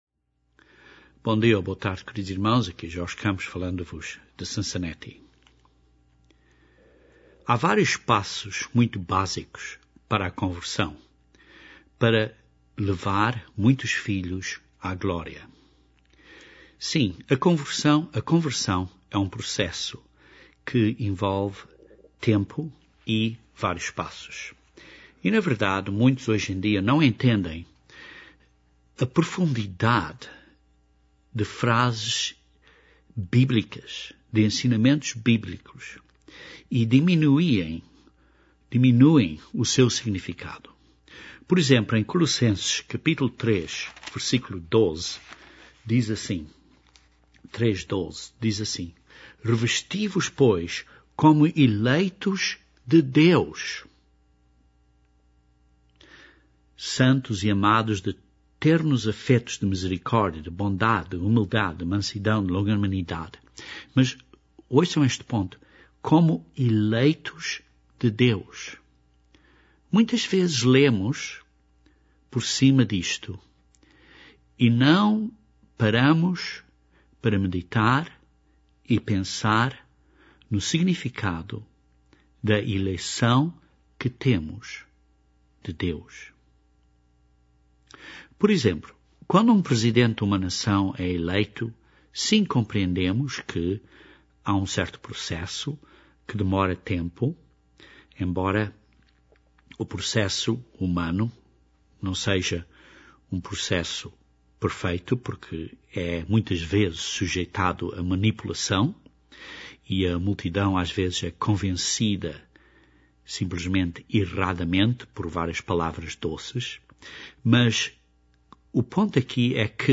Este sermão fala desta eleição feita por Deus Pai. O sermão explica que a conversão é um processo, um caminho apertado e difícil, que conduz à vida eterna.